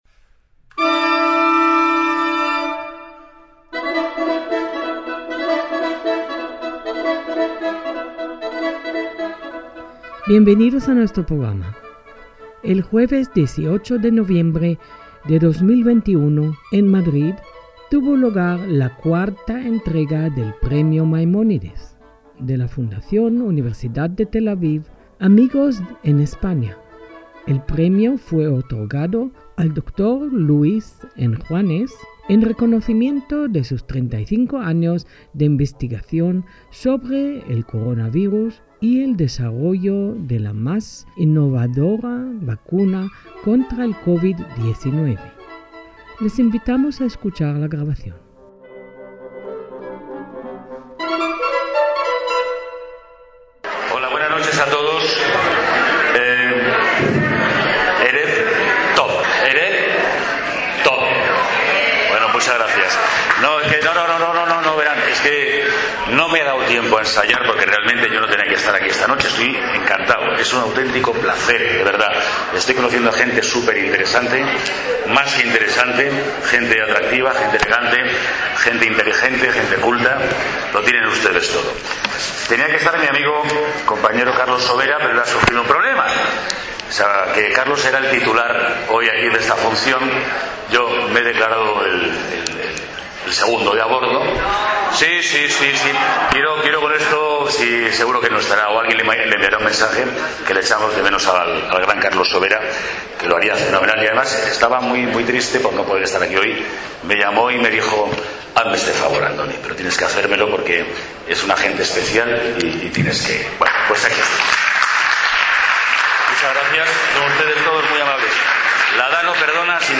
Ceremonia de entrega del IV Premio Maimónides al dr. Luis Enjuanes (Madrid, 18/11/2021)
ACTOS EN DIRECTO